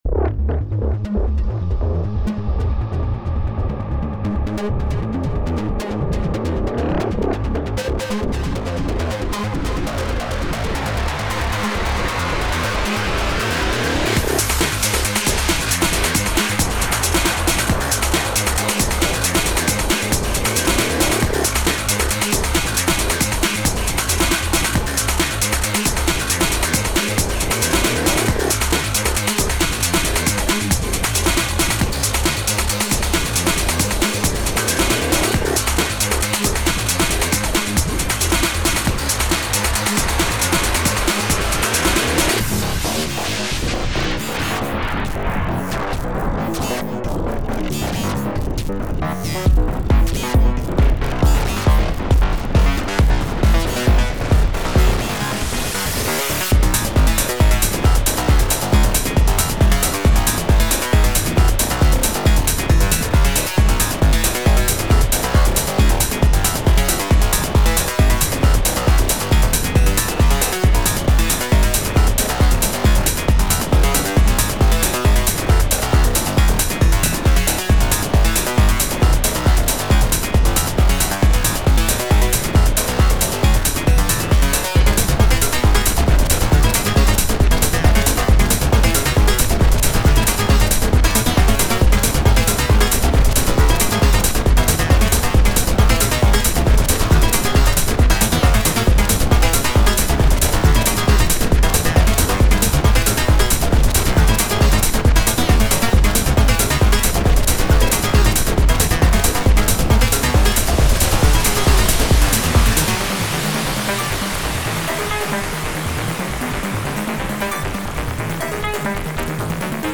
タグ: EDM かっこいい 電子音楽 コメント: サイバー風のTrance楽曲。